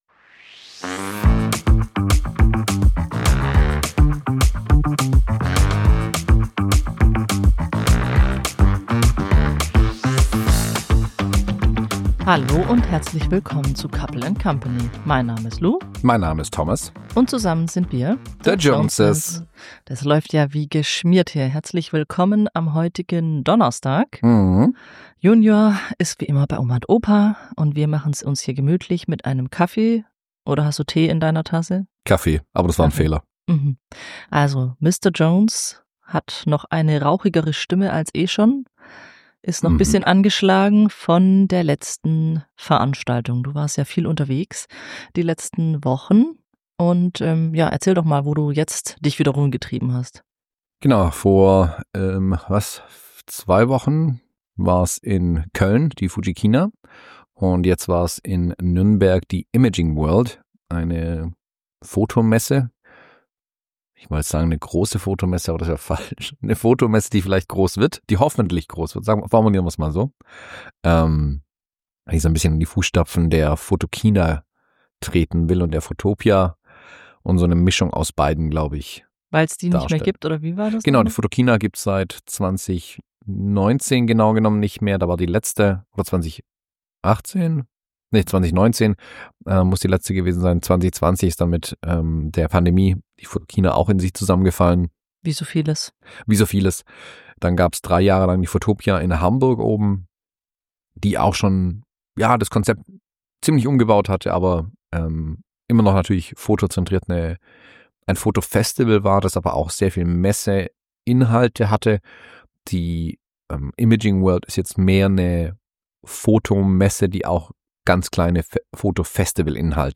052: Coffee Date - Mit rauer Stimme durchs Messegetümmel ~ Couple & Company Podcast